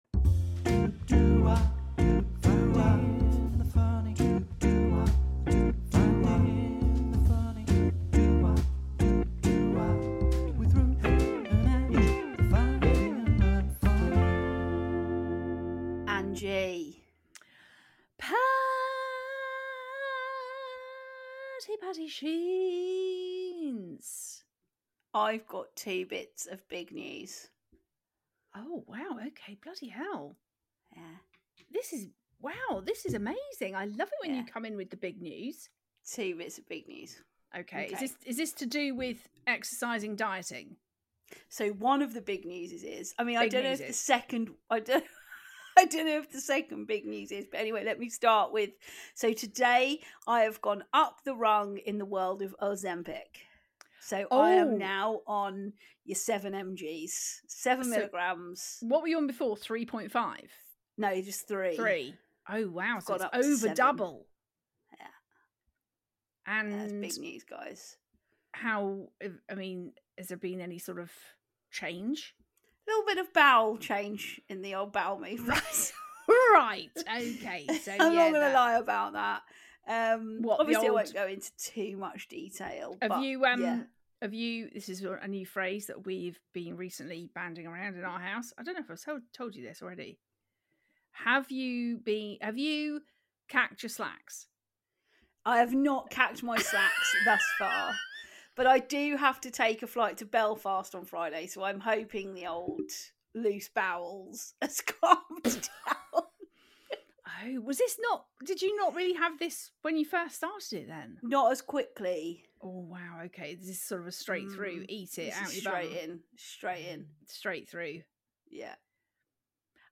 We have a cracking voice note from a listener, which has now potentially become a whole new segment, bosh!